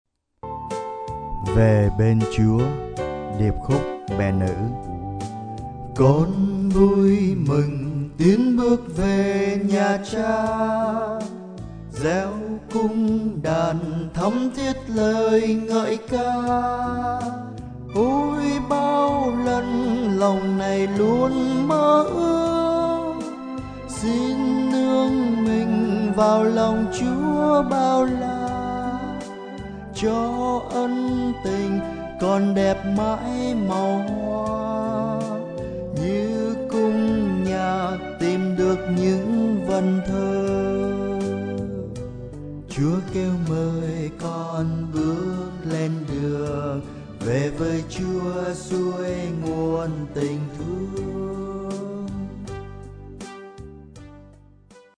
VeBenChua_Sop.mp3